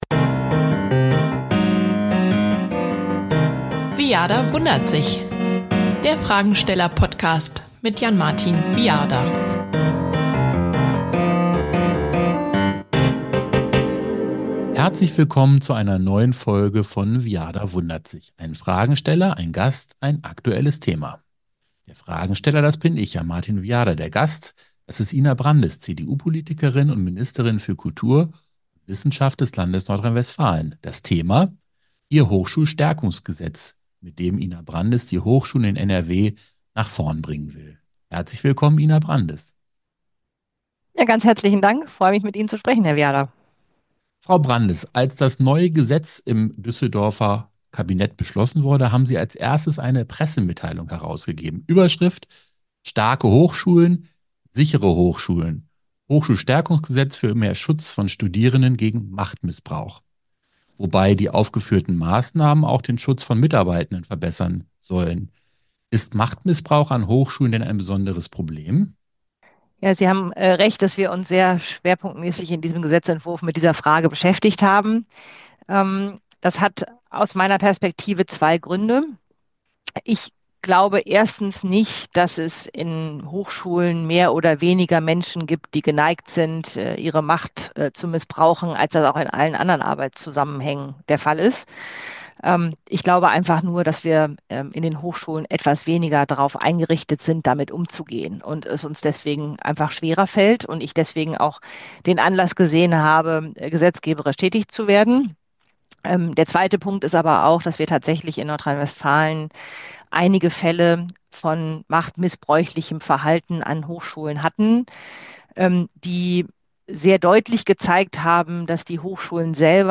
Wie NRW-Wissenschaftsministerin Ina Brandes mit dem neuen "Hochschulstärkungsgesetz" Studierende und Mitarbeitende besser vor Machtmissbrauch schützen will: ein Gespräch